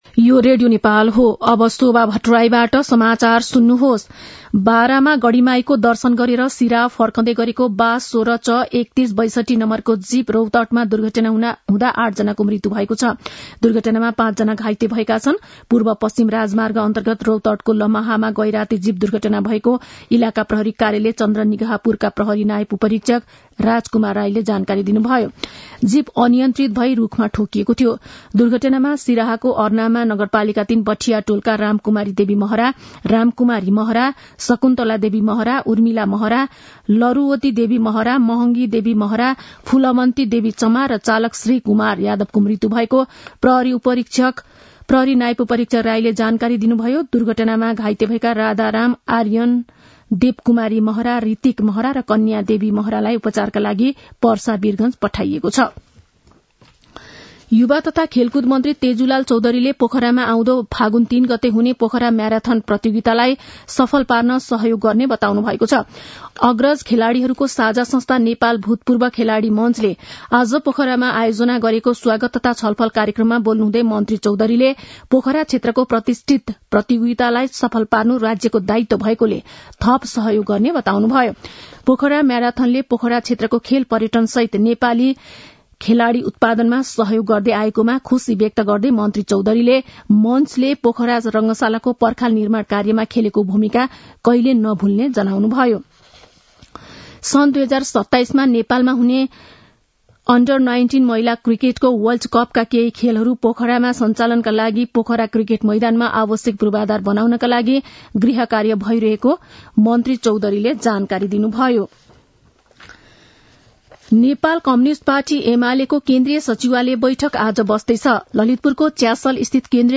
An online outlet of Nepal's national radio broadcaster
मध्यान्ह १२ बजेको नेपाली समाचार : २६ मंसिर , २०८१
12-am-nepali-news-1-7.mp3